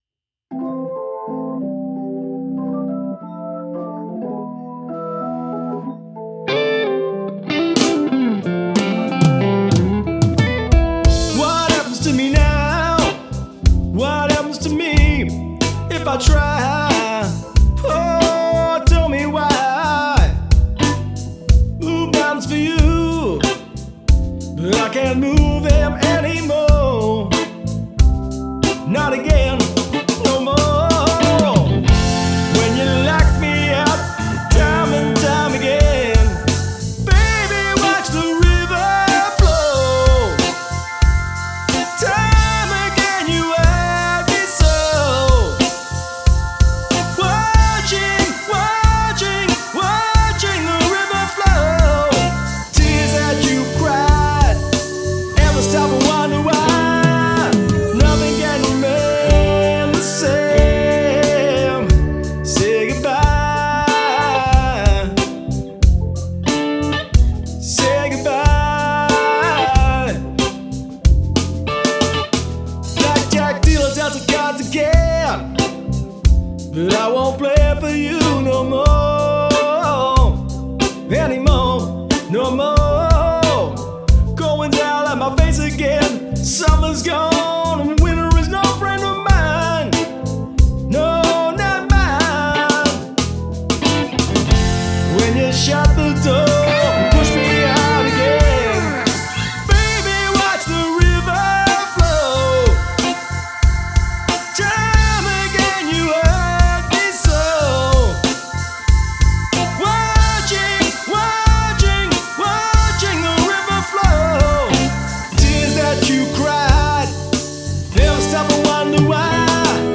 Guitars, Vocals & Bass
Hammond Organ, Wurlitzer & Fender Rhodes
blues-edged rock